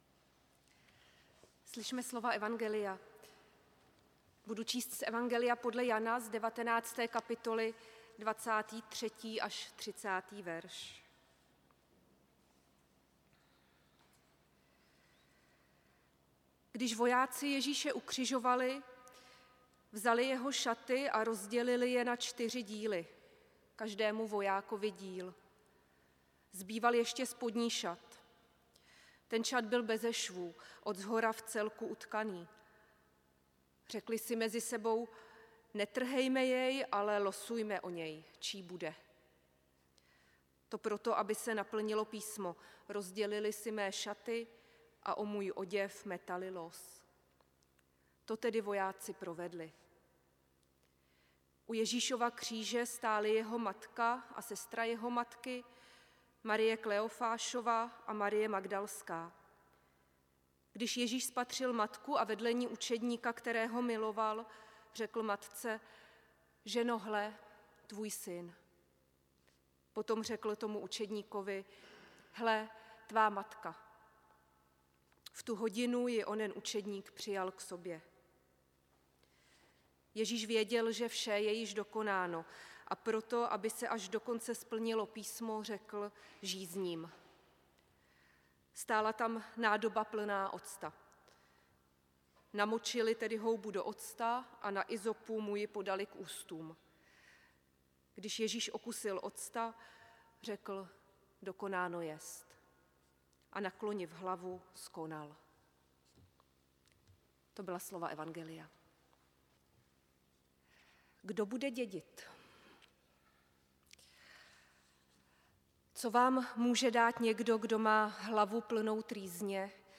audio kázání podle Jana 19: pod křížem